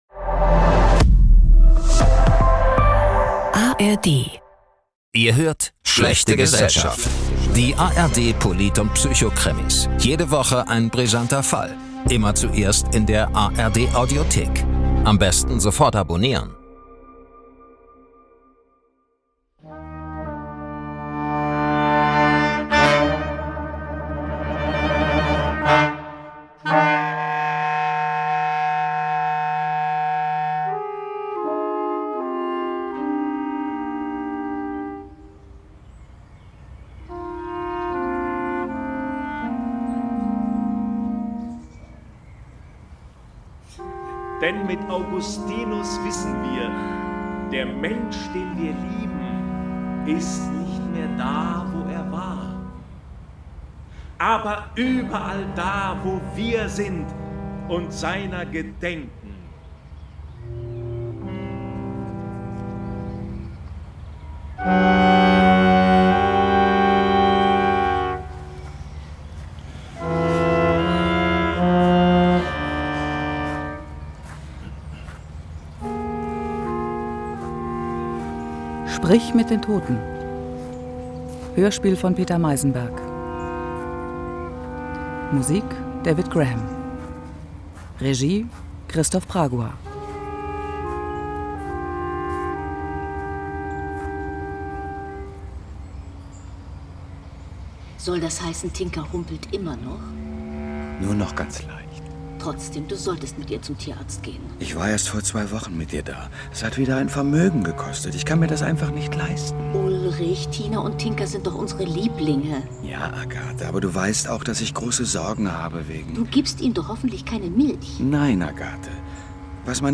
Beschreibung vor 6 Tagen Bestatter Wenzel will den Tod neu denken - doch sein Mitarbeiter Ulrich entdeckt, dass in den Särgen mehr steckt als Trauer. Ein Gesellschaftskrimi über Macht, Moral und das Geschäft mit dem Sterben.